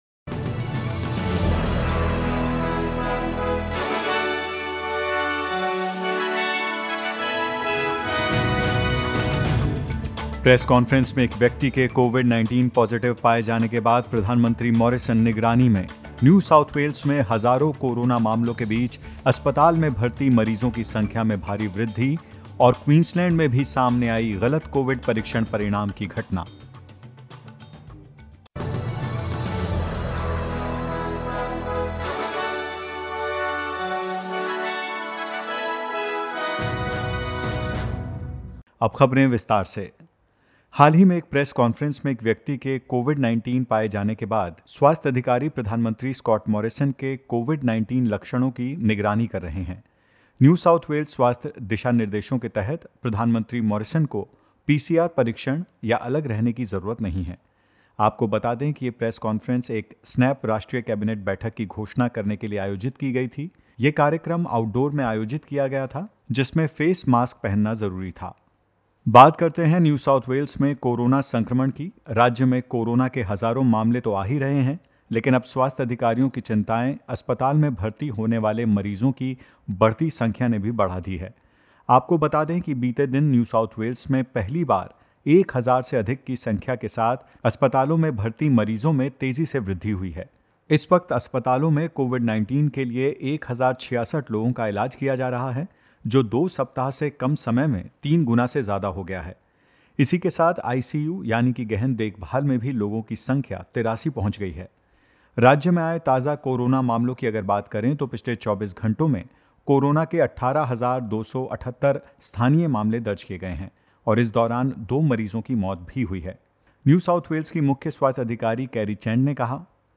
In this latest SBS Hindi news bulletin: Prime Minister Scott Morrison monitors for COVID-19 symptoms; Glenn McGrath tests positive for coronavirus and more.